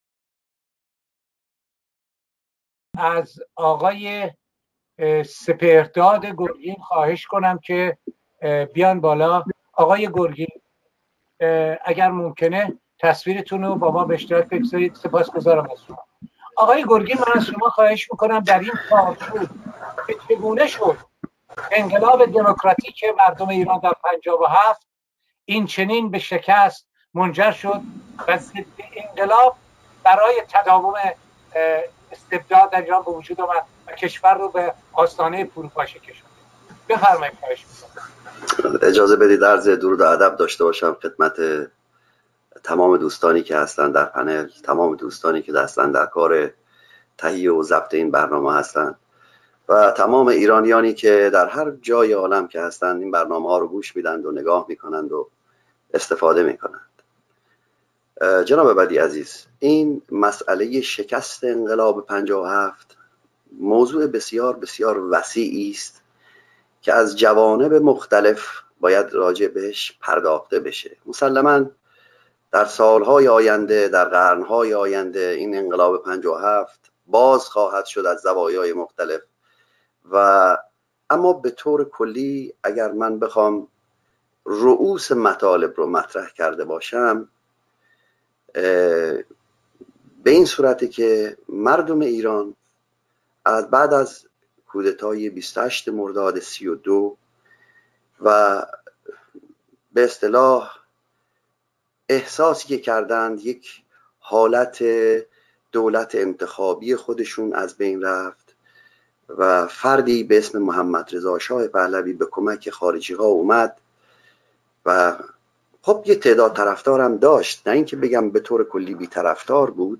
به گزارش گذار (سامانه دموکراسی و داد)، وبینار سراسری گذار از استبداد اسلامی به دموکراسی، با موضوع راهکارهای میدانی برای گذار خشونت پرهیز از استبداد اسلامی به دموکراسی، در جهت حمایت و شرکت فعال ایرانیان خارج از کشور از جنبش اعتراضی برای تغییرات بنیادی در حاکمیت سیاسی، با حضور جمعی از کنشگران مدنی، صاحب‌نظران و فعالان سیاسی، شامگاه یک‌شنبه ۲۴ فوریه ۲۰۱۹ (۵ اسفندماه ۱۳۹۷) برگزار شد.